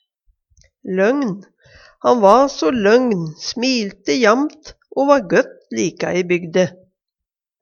løgn - Numedalsmål (en-US)